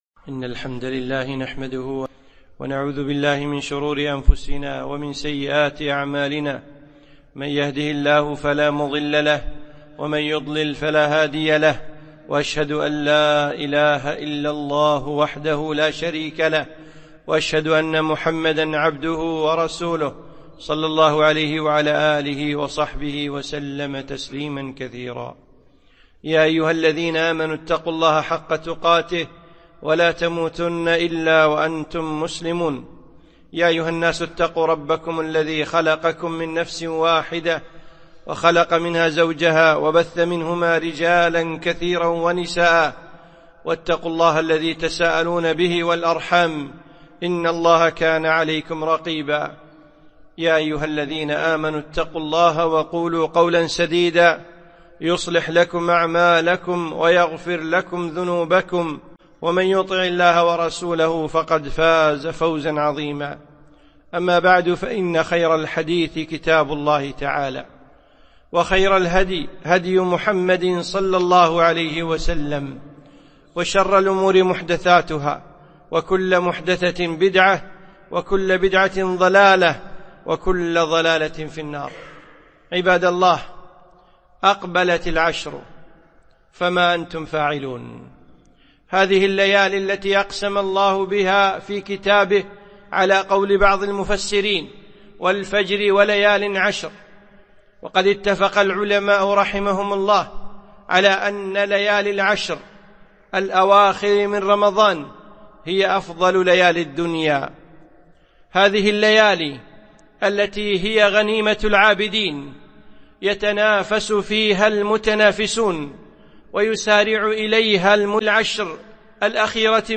خطبة - العشر الأواخر